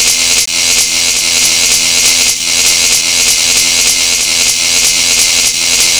electroshock-mono_loop.wav